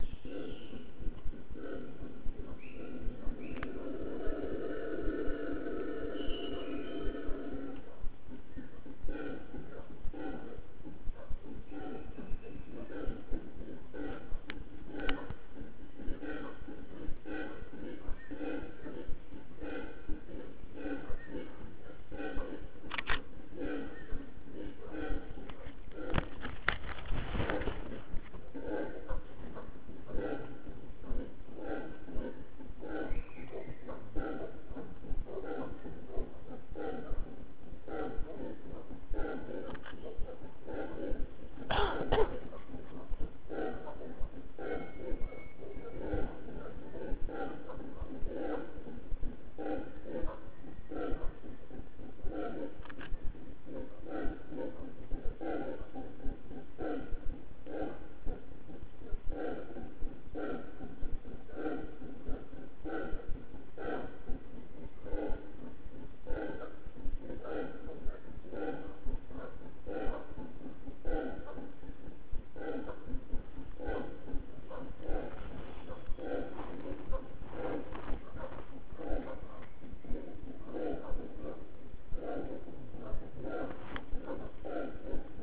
Two groups of howler monkeys met in the canopy.
howler.wav